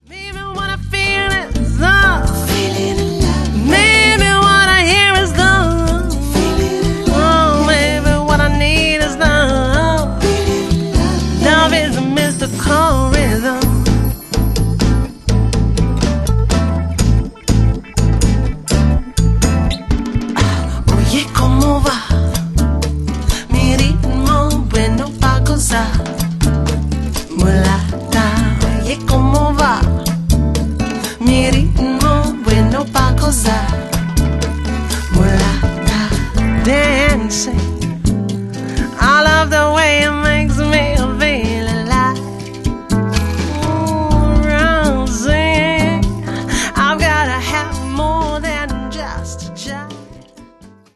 Recorded at the Cutting Room in NYC